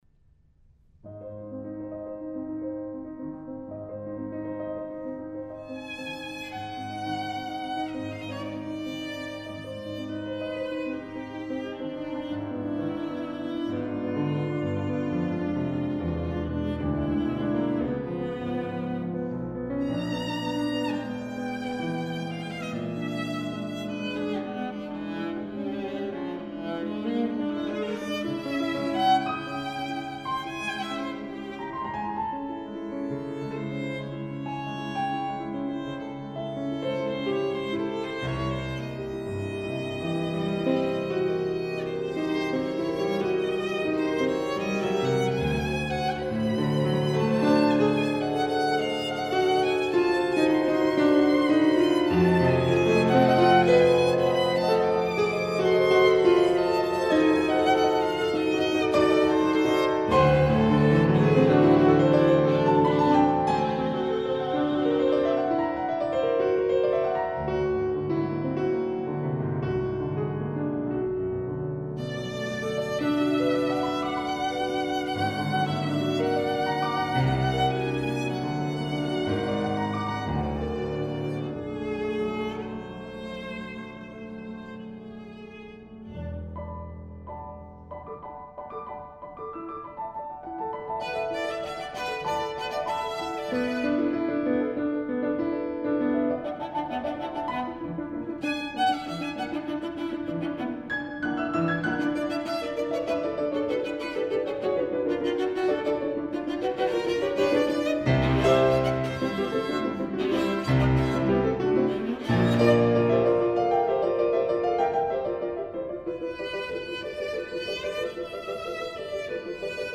Klangbeispiele (Solo & Kammermusik)
E. Bloch: Suite für Viola und Klavier